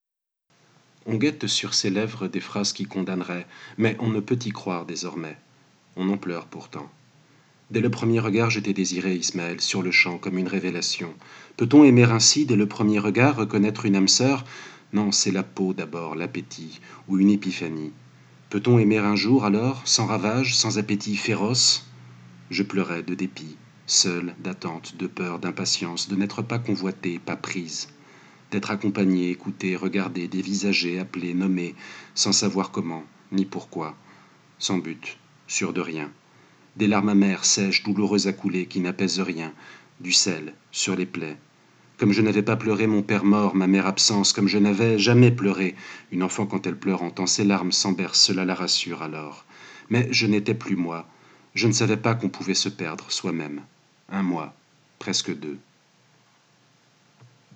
Pour l’occasion, et parce que j’ai vraiment aimé ce que j’ai lu, plutôt que juste en reproduire les photos j’ai choisi de vous lire les pages en question :